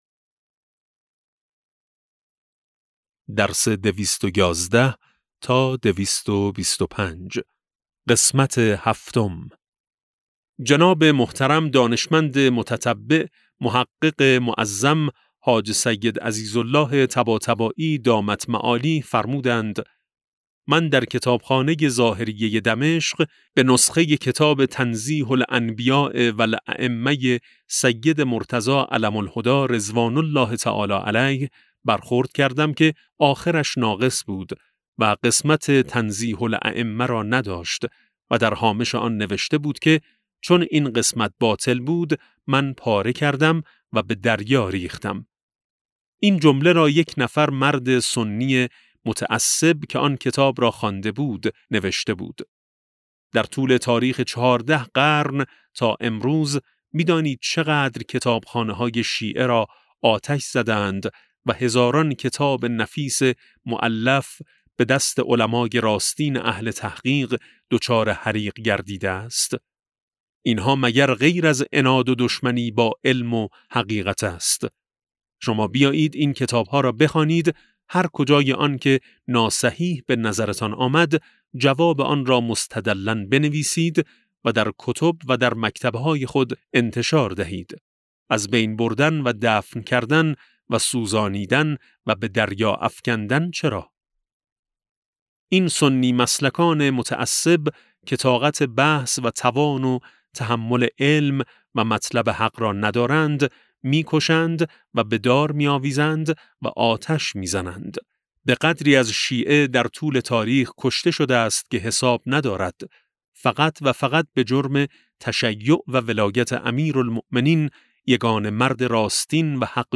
کتاب صوتی امام شناسی ج15 - جلسه7